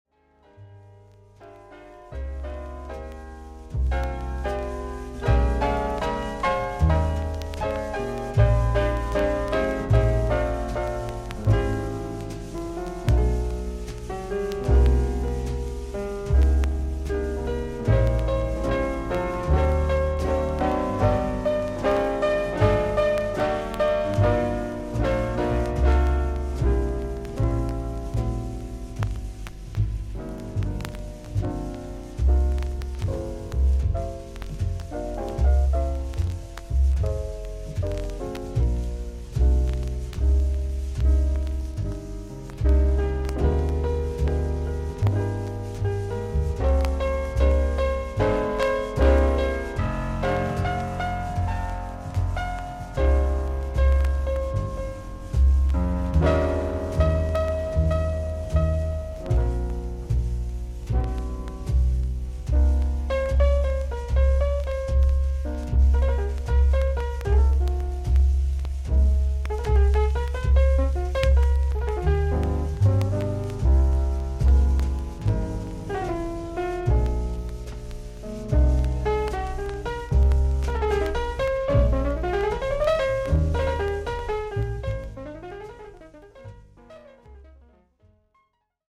B3序盤に3mmのキズ、周回ノイズあり。
ほかはVG+:盤面に長短のキズがあり所々に少々軽いパチノイズの箇所あり。音自体はクリアです。
ジャズ・ピアニスト。